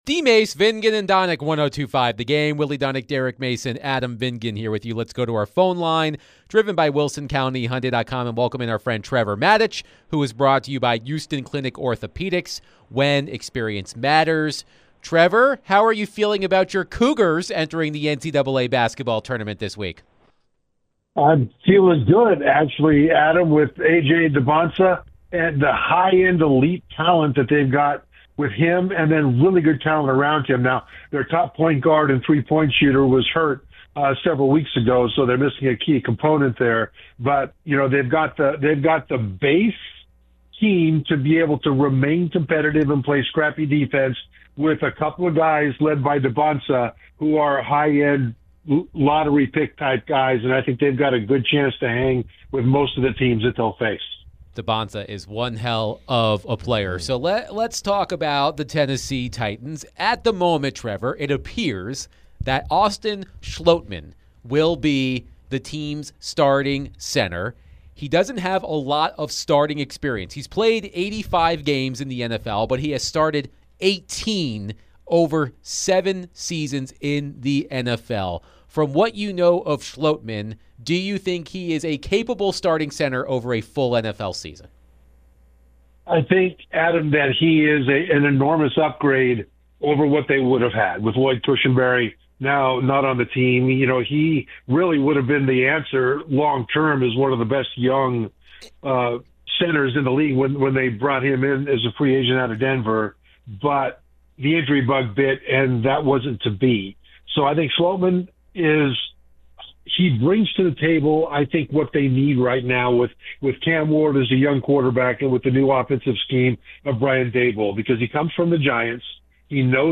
ESPN NFL Analyst Trevor Matich joined DVD to discuss all things Titans, NFL draft, FA, and more.